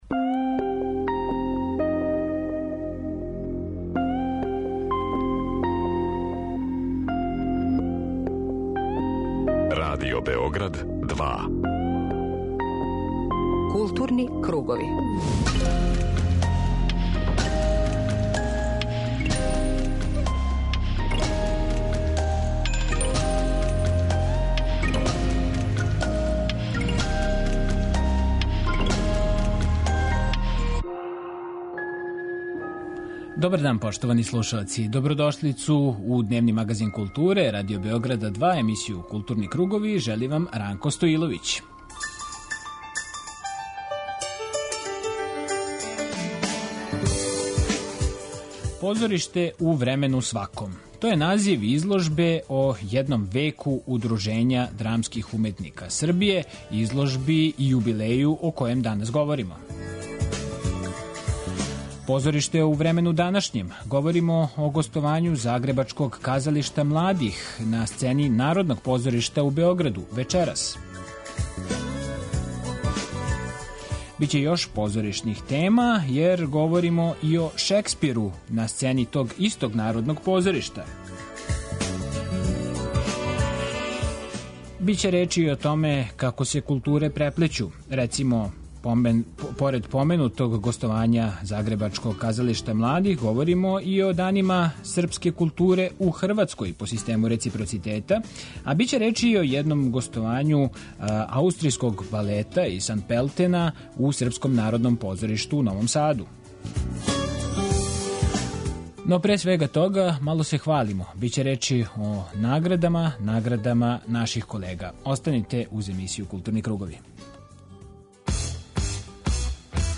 Дневни магазин културе